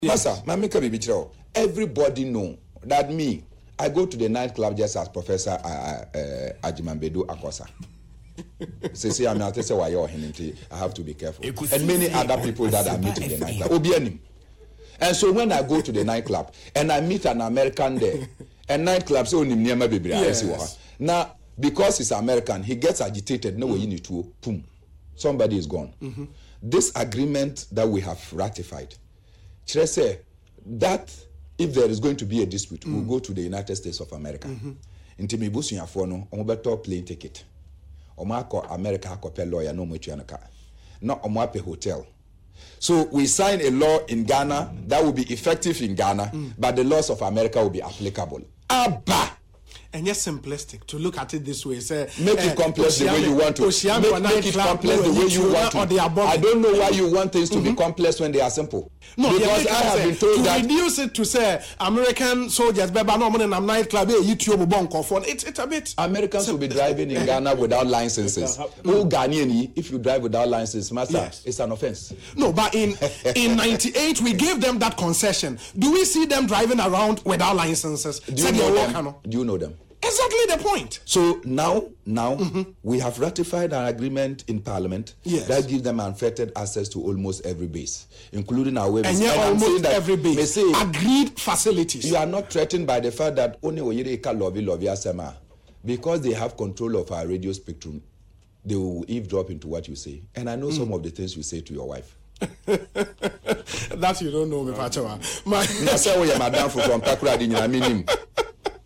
Bernard Mornah was speaking on Asempa FM’s Ekosii Sen on Wednesday.